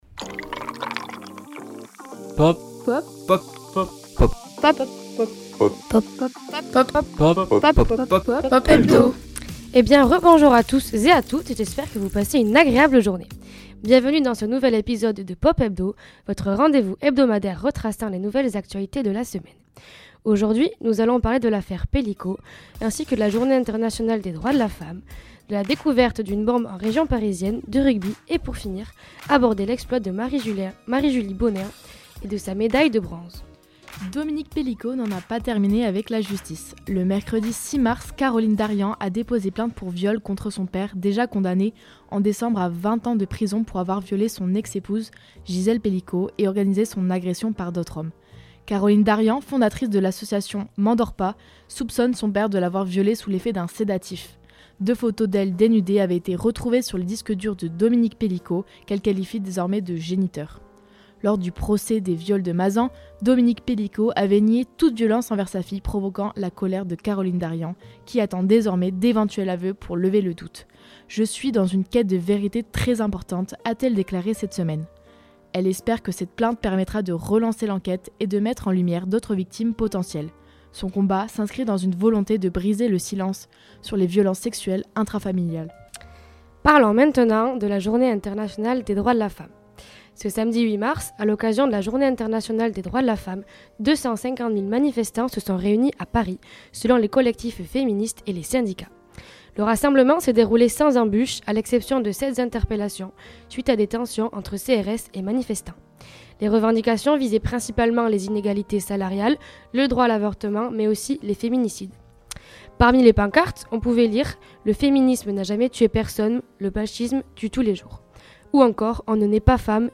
Programme à retrouver en direct tous les lundis entre 12h et 13h sur Radio Campus Bordeaux (merci à eux), et en rediffusion sur notre site Web et Spotify !